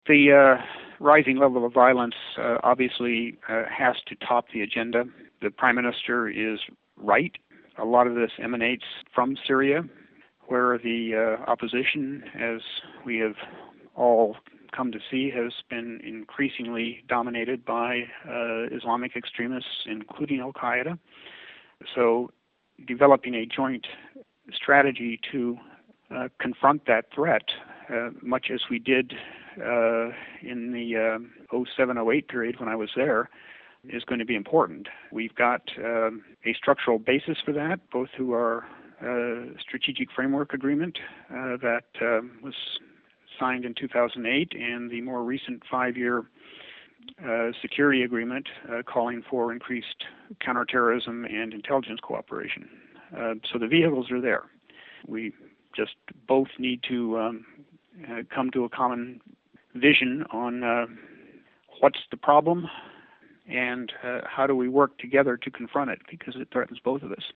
interview with Former US Ambassador to Iraq Ryan Crocker